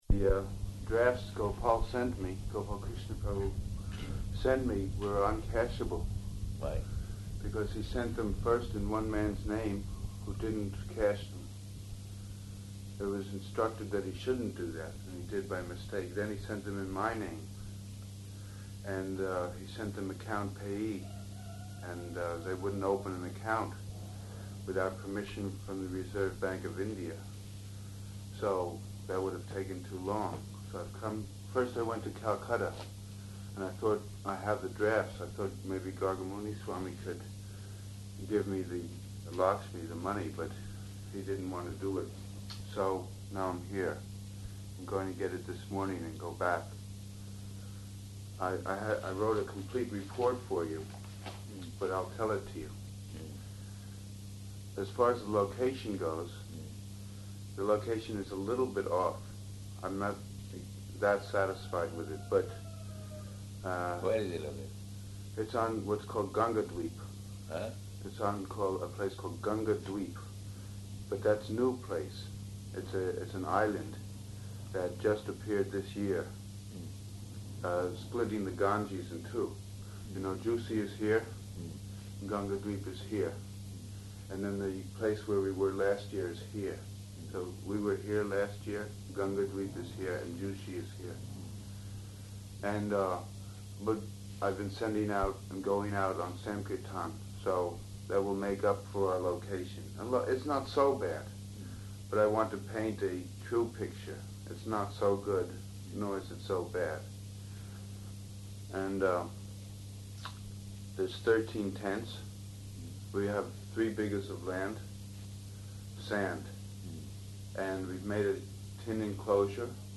Morning Discussion about Kumbha Melā